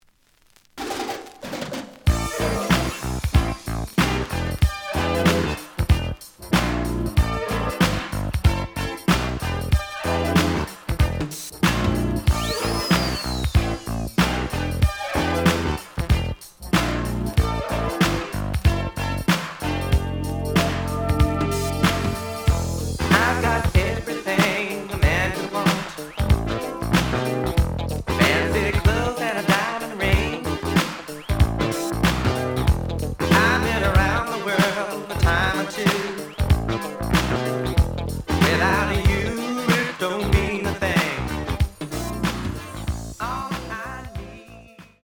The audio sample is recorded from the actual item.
●Genre: Disco
Edge warp. But doesn't affect playing. Plays good.)